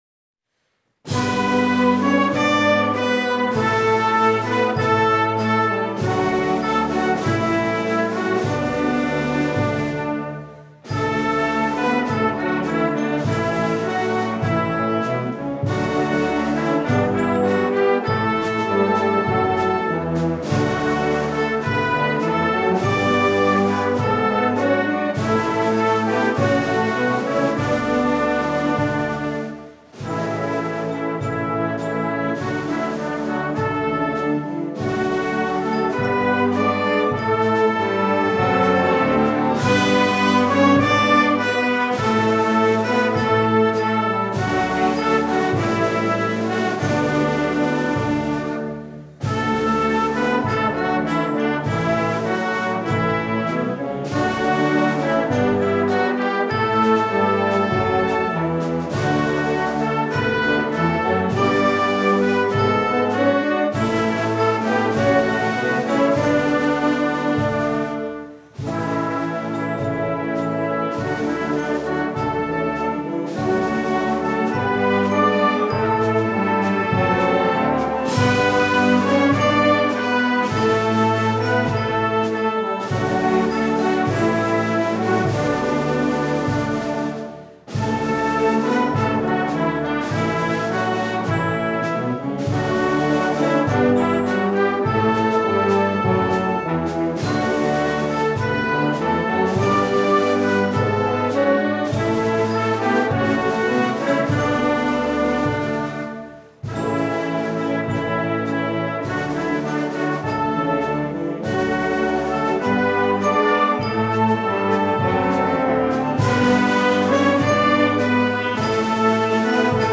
１０月９日、長野県伊那北高校の創立１００周年記念式典が伊那文化会館で開かれました。
在校生による校歌の演奏がありました。
この日のために日本フィルハーモニー交響楽団によって制作された楽譜で吹奏楽部と合唱部が校歌を演奏しました。